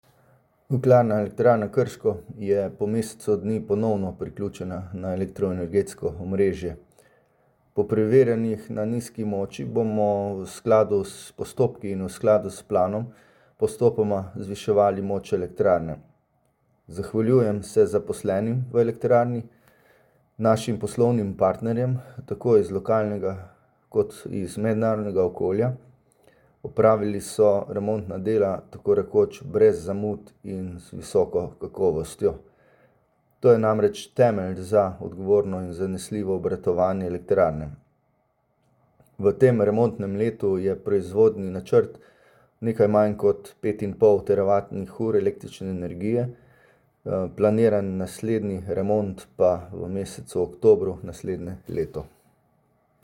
• Tonska izjava